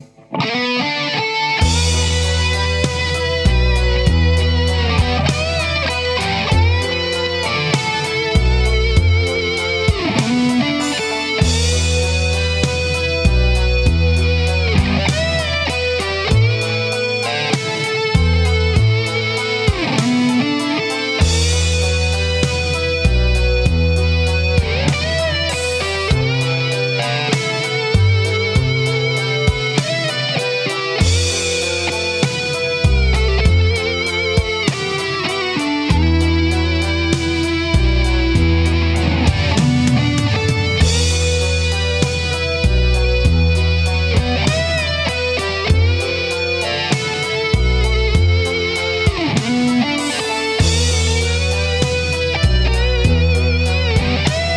Le 05 et 06/08/2006 au studio "Mafaldine", Lamalou, France
Guitare
Claviers, Chant, Choeurs